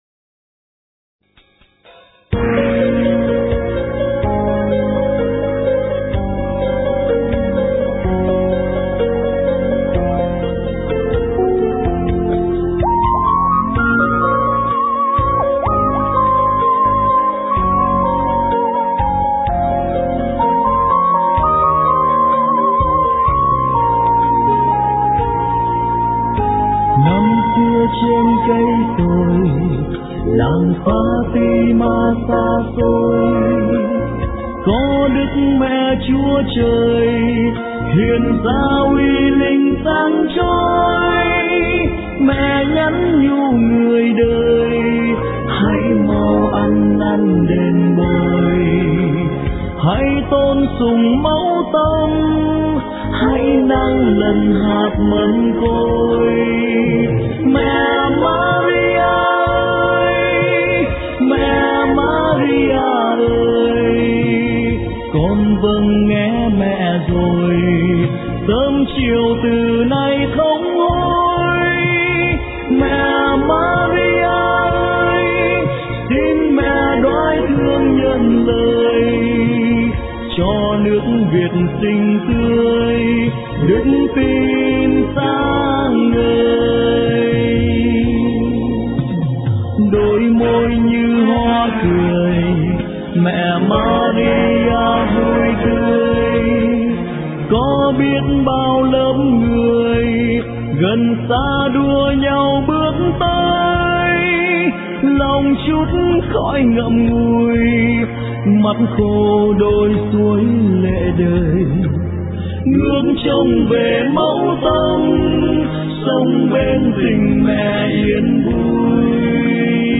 * Thể loại: Đức Mẹ